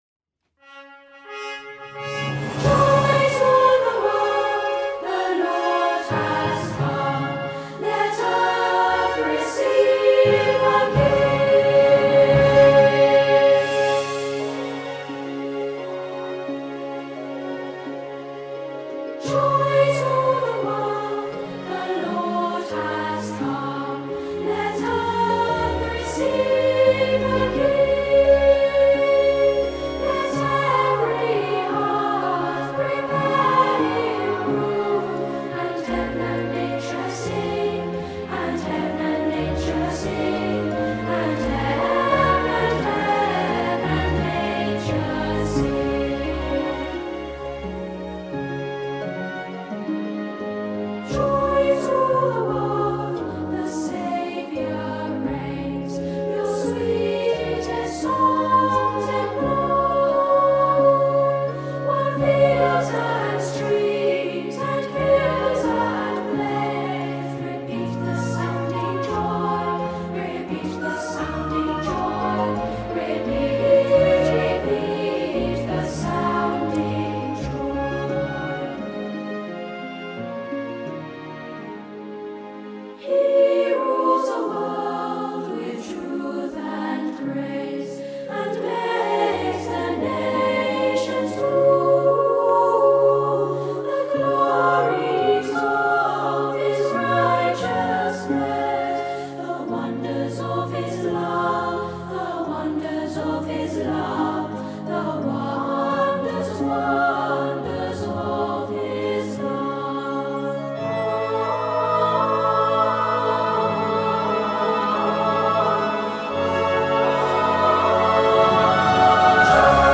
Opening van deze zondag met muziek, rechtstreeks vanuit onze studio.
koorversie
Kerstmuziek.mp3